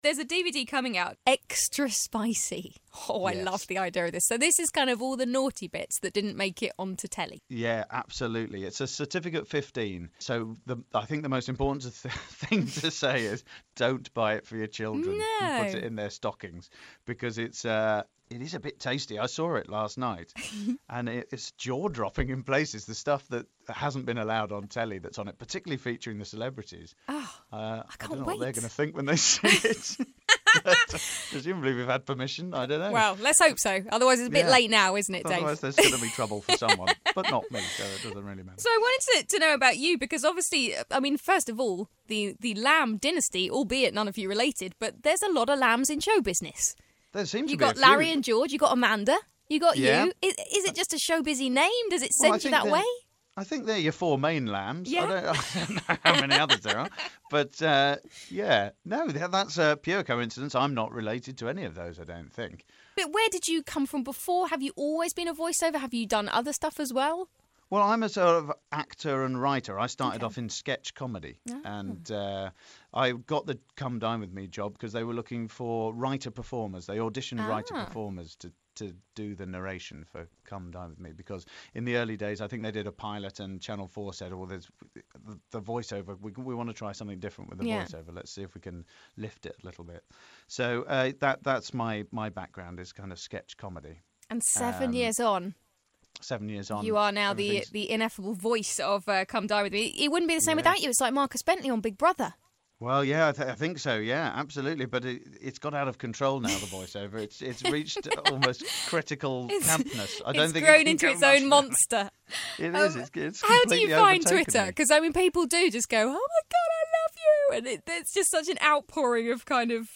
Glide's Morning Glory Interview with Dave Lamb Part 2